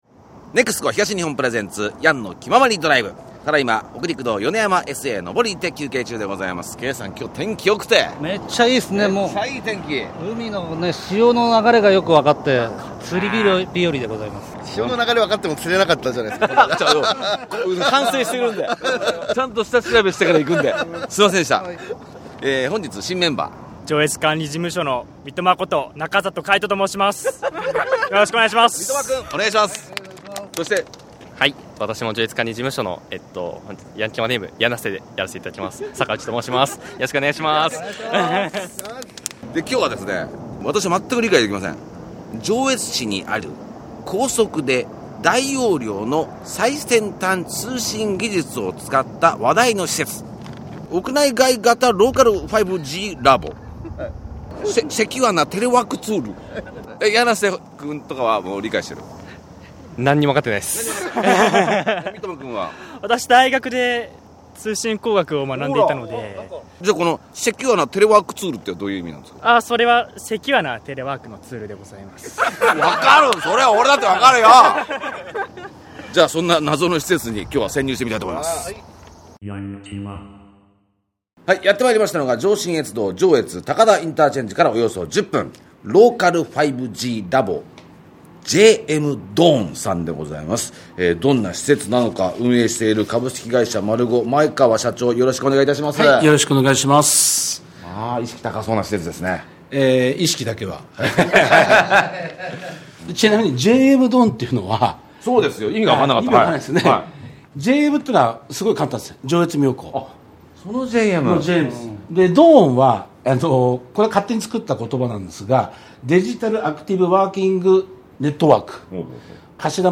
ロケ当日は、小学生たちにeスポーツの体験会？の日で、たくさんの子供たちがパソコンでゲーム楽しんでましたよ。